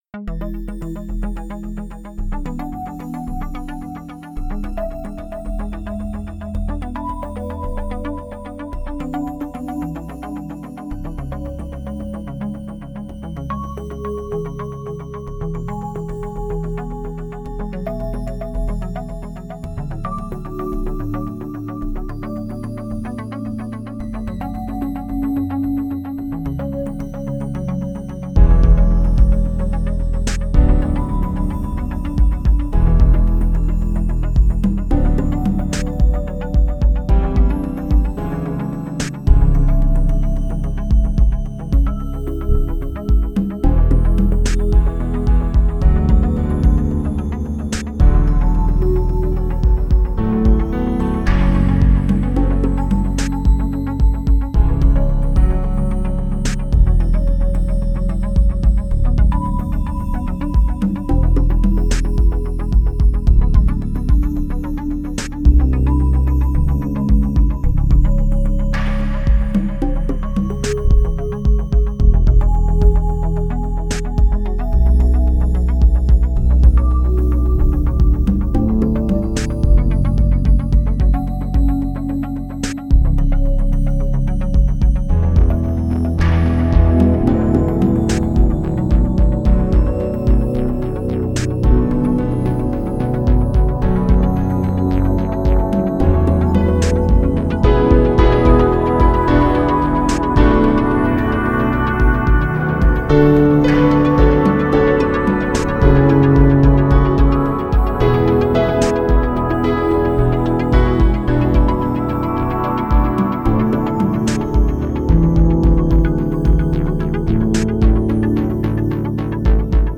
Les autres sons et motifs sont de moi : synthétiseurs, basse, percussions et structure du morceau final.
C’est parfait pour commencer la journée en douceur 🙂